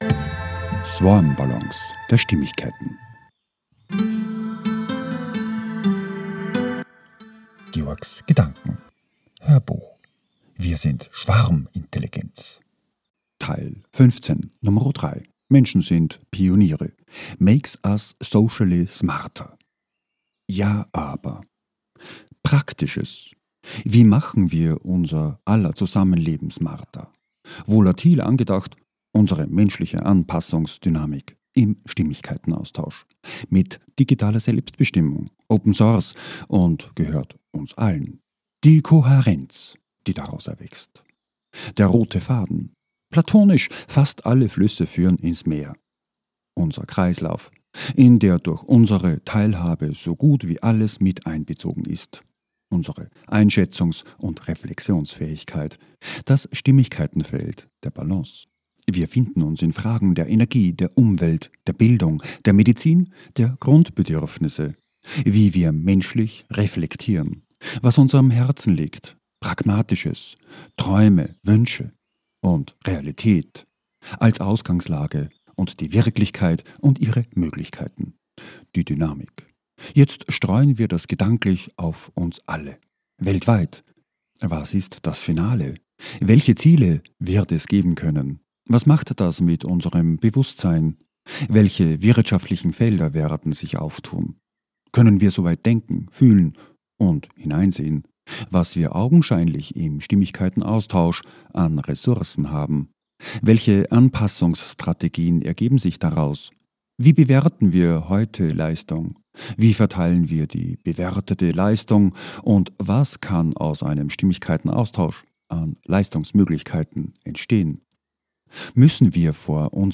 HÖRBUCH - 015.3 - WIR SIND SCHWARMINTELLIGENZ - Menschen sind Pioniere - Makes us socially SMARTER